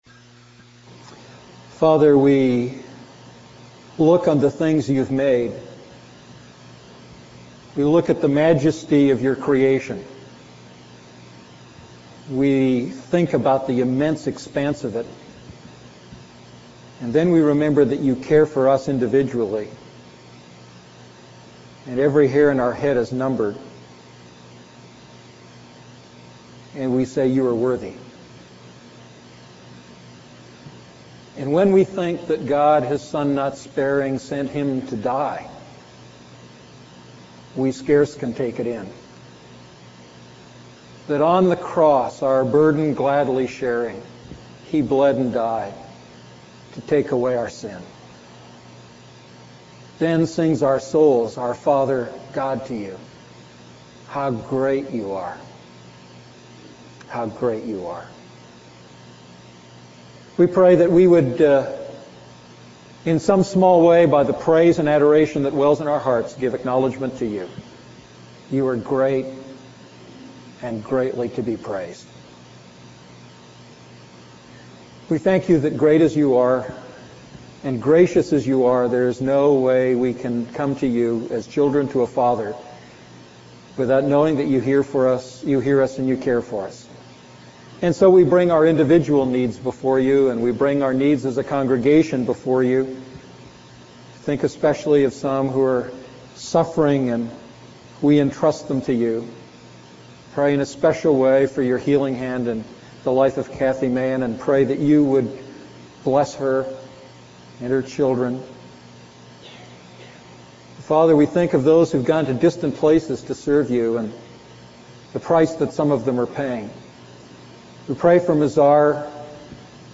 A message from the series "Wise Walking."